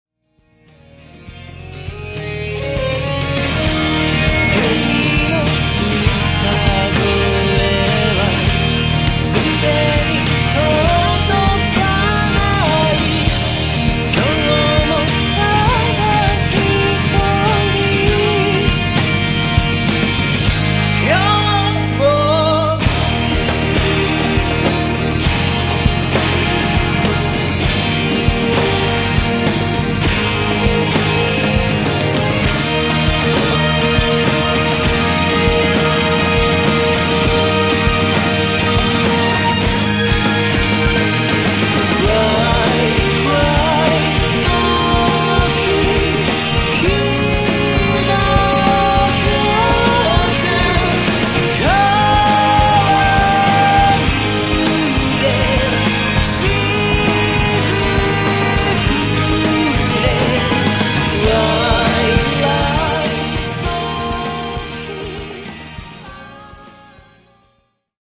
２曲目の方が心地よいです。
おもしろいサウンドです。